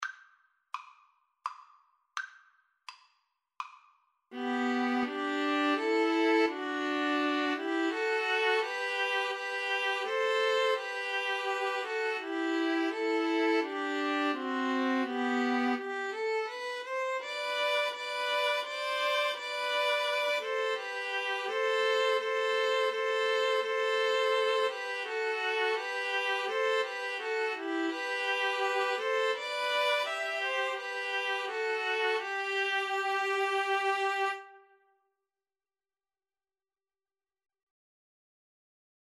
G major (Sounding Pitch) (View more G major Music for String trio )
3/4 (View more 3/4 Music)
String trio  (View more Easy String trio Music)